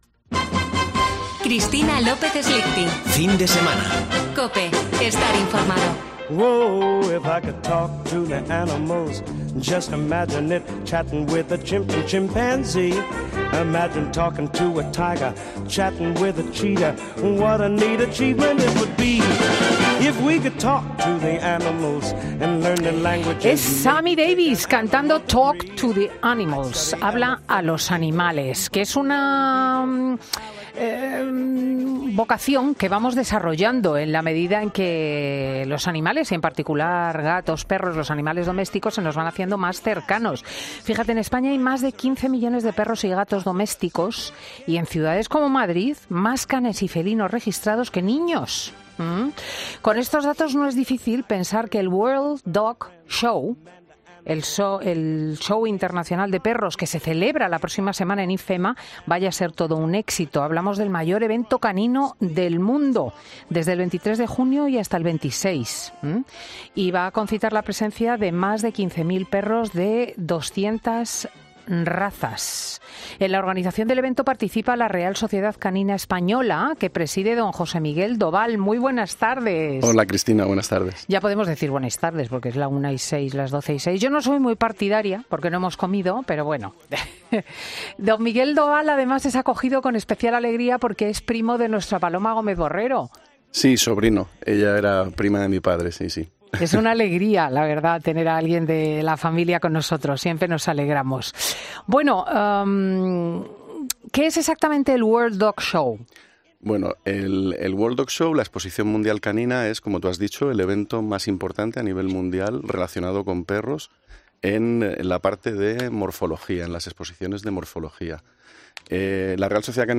Redacción digital Madrid - Publicado el 19 jun 2022, 13:31 - Actualizado 18 mar 2023, 16:42 3 min lectura Descargar Facebook Twitter Whatsapp Telegram Enviar por email Copiar enlace Escucha ahora 'Fin de Semana' . "Fin de Semana" es un programa presentado por Cristina López Schlichting , prestigiosa comunicadora de radio y articulista en prensa, es un magazine que se emite en COPE , los sábados y domingos, de 10.00 a 14.00 horas.